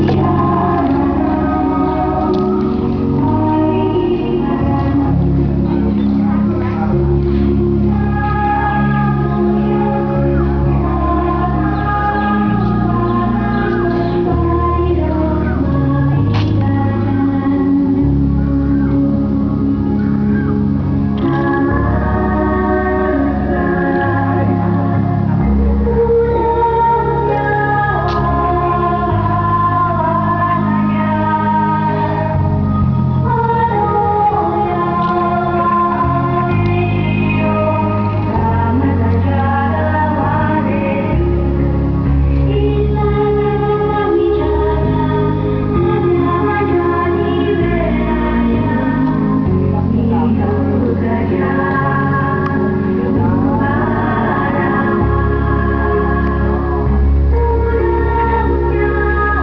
Ich setzte mich auf einen Stuhl an einen kleinen See und lauschte der meditativen Musik eines Chores.
A beautiful music came from the tape or CD, i enjoyed the view on the lakes.